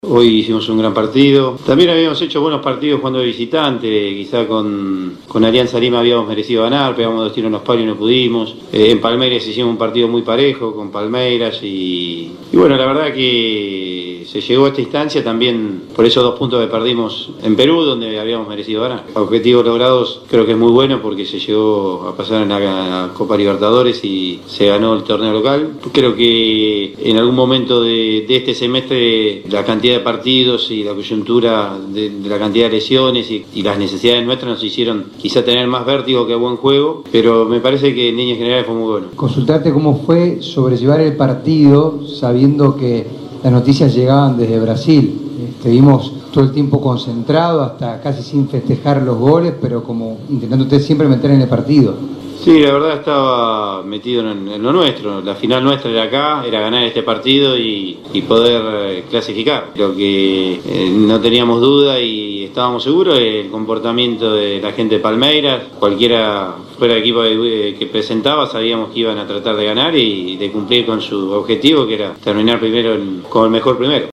GUILLERMO BARROS SCHELOTTO POST PARTIDO: